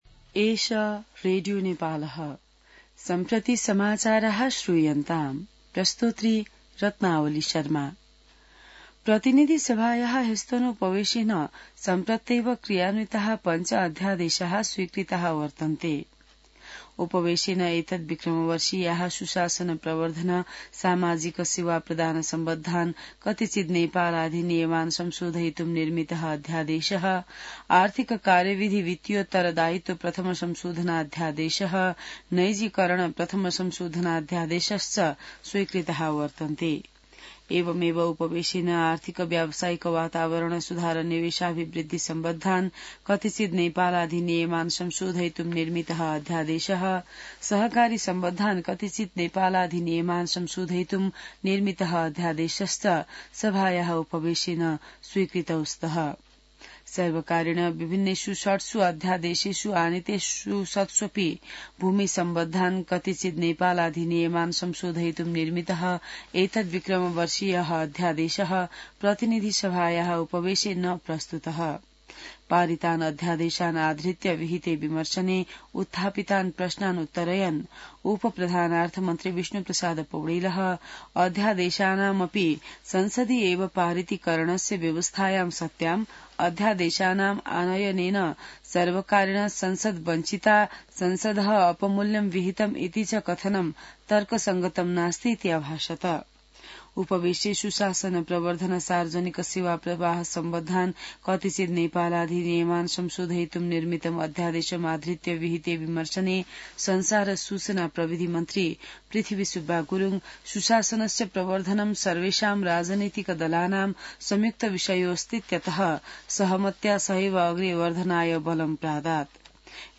संस्कृत समाचार : २३ फागुन , २०८१